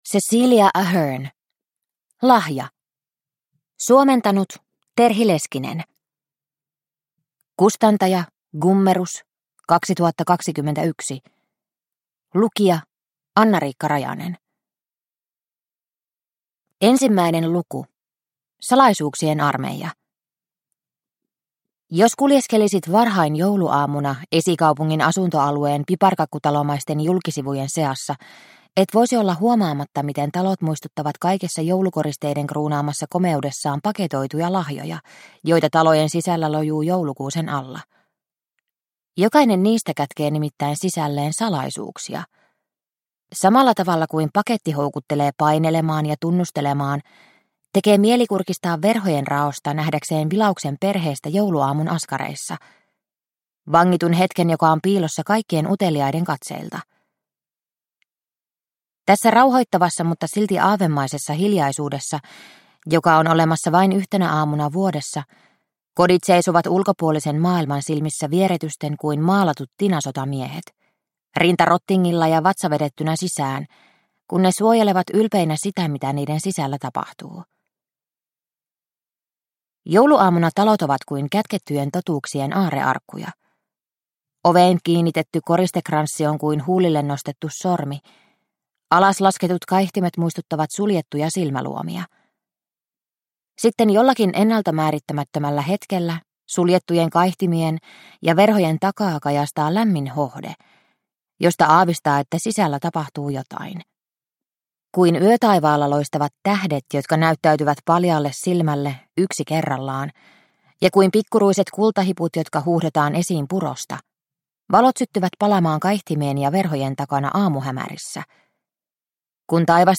Lahja – Ljudbok – Laddas ner